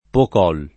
Pocol [ pok 0 l ]